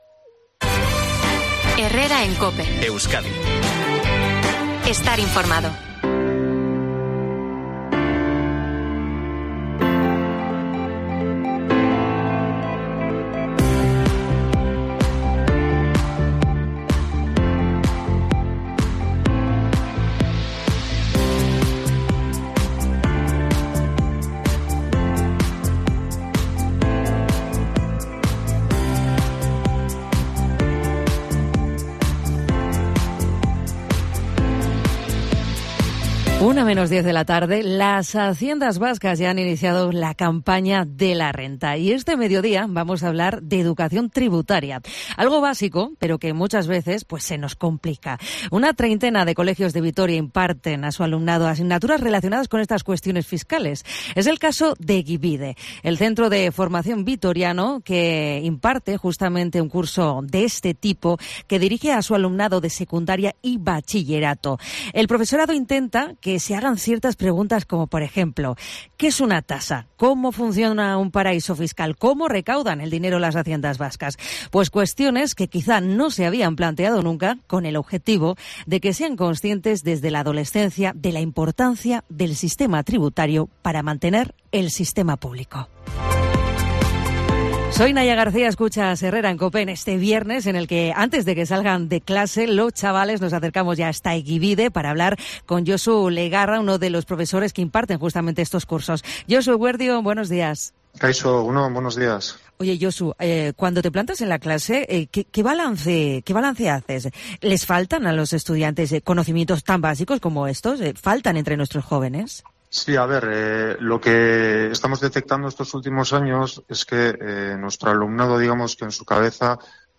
Esta semana que ha arrancado la campaña de la renta en los tres territorios vascos hemos querido hablar con uno de los docentes que imparten formación tributaria en Euskadi.